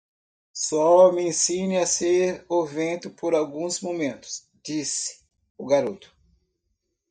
Read more Noun Verb Frequency B2 Pronounced as (IPA) /ɡaˈɾo.tu/ Etymology Cognate with French gars In summary Unknown.